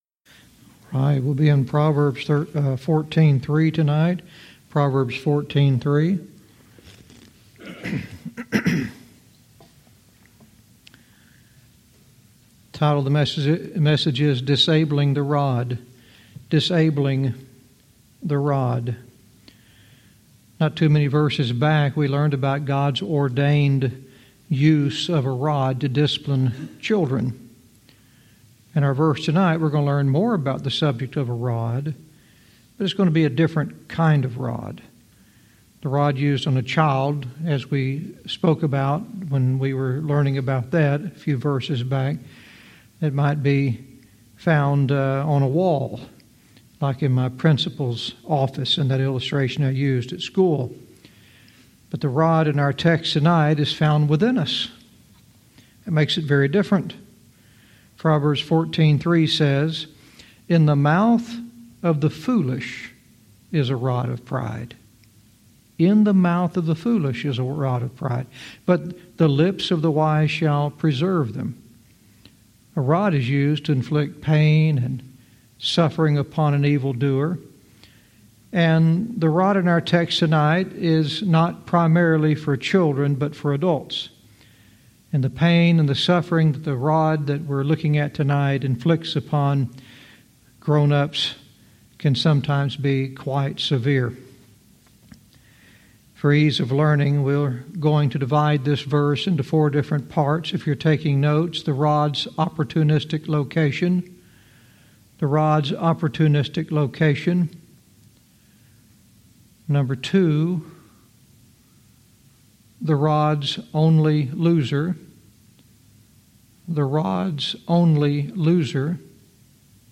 Verse by verse teaching - Proverbs 14:3 "Disabling the Rod"